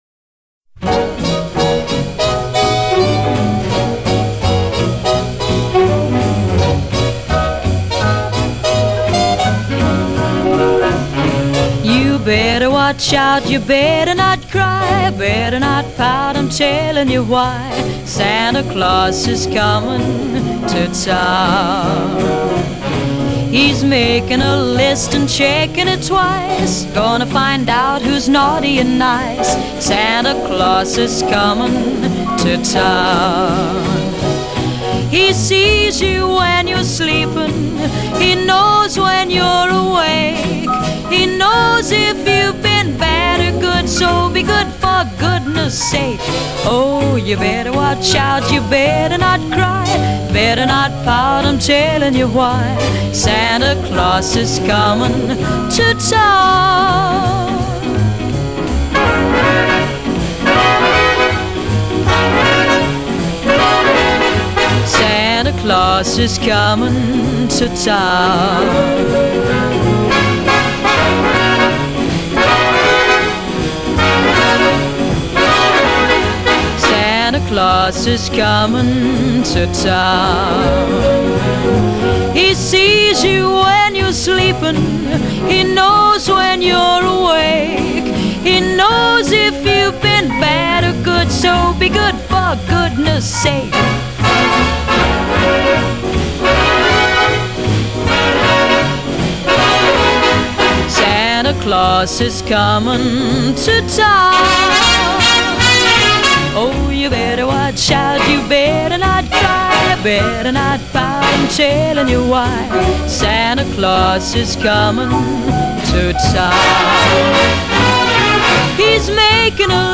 音乐类型： Pop, Christmas　　　　　　　　　　　　　  　.